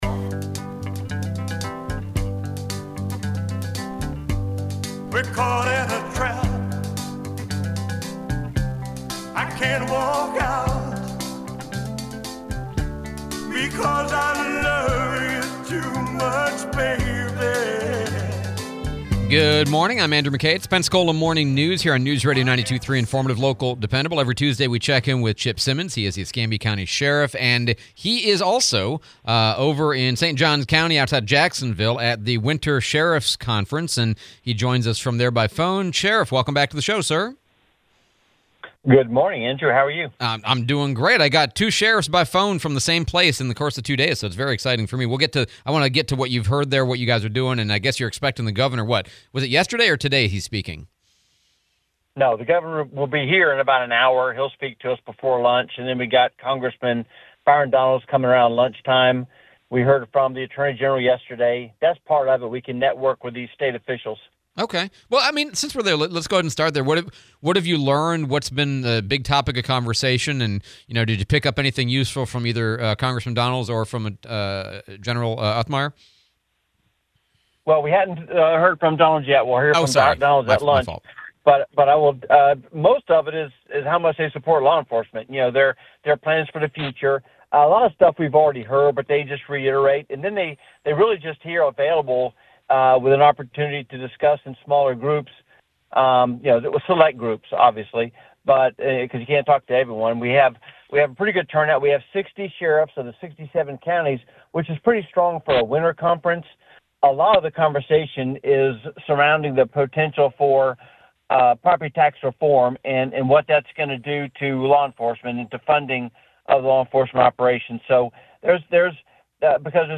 01/27/26 Sheriff Simmons interview